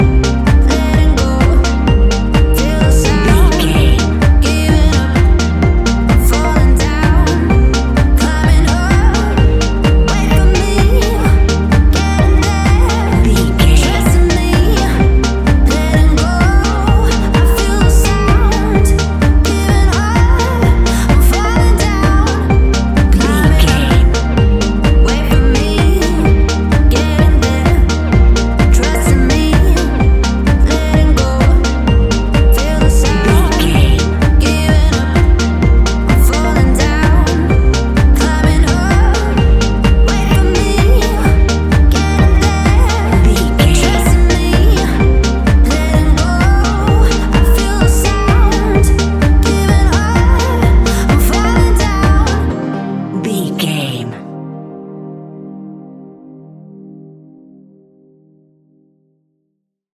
Ionian/Major
D♯
house
electro dance
synths
techno
trance
instrumentals